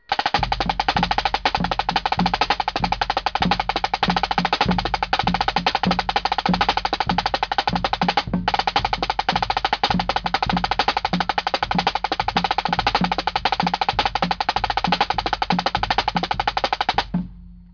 La batterie
Le pupitre batterie est composé de caisses claires écossaises, d'une grosse caisse et de toms.
La grosse caisse (basse), les toms (ténors) sont des tambours sans timbres que l'on joue avec des mailloches souples.
batterie.wav